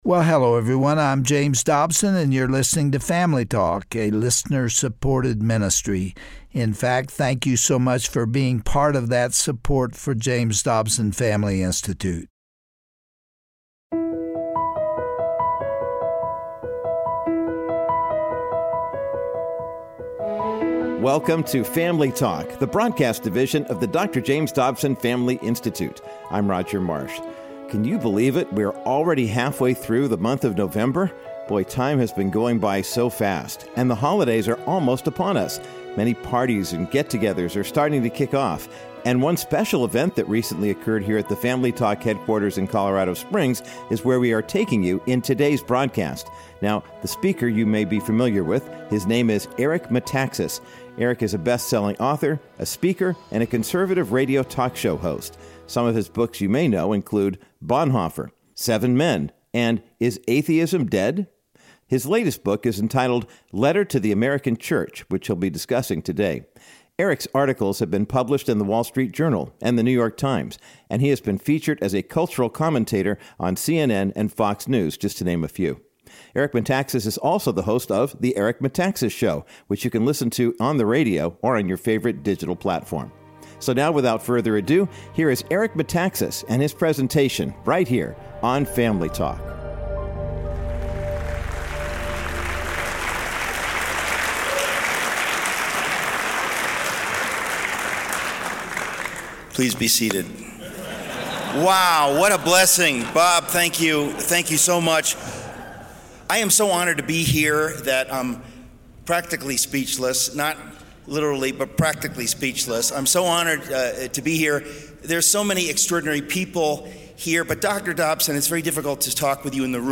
On today’s edition of Family Talk, author Eric Metaxas gives an empowering presentation and urges Americans to wake up and preach the whole counsel of God. In a time where good is called evil, and evil is called good, now is the time for the Church to awaken and take her rightful place.